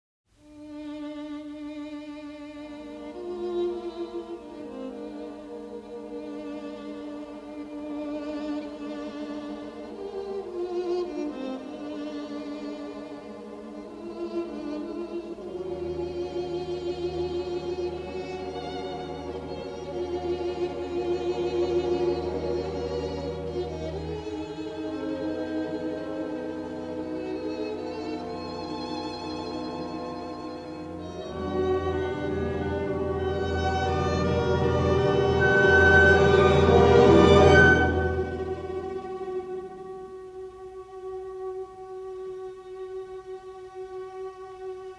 in the best-possible monaural sound.